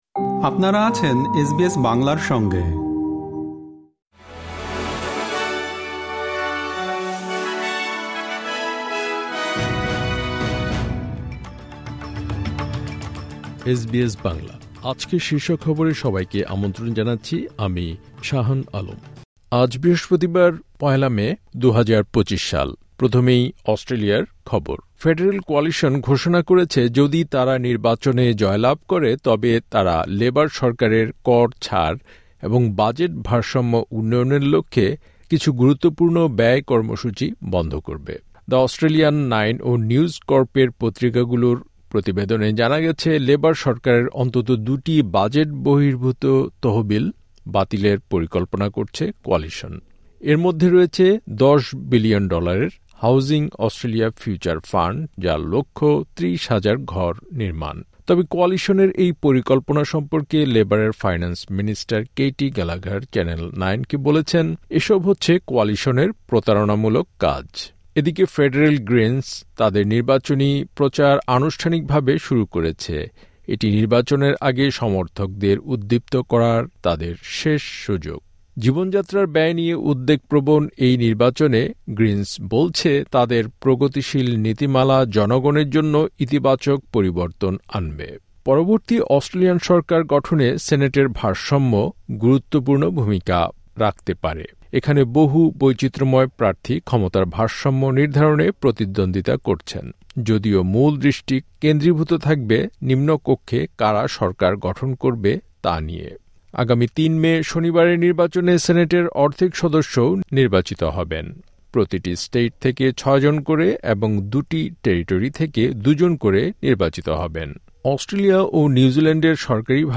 আজকের শীর্ষ খবর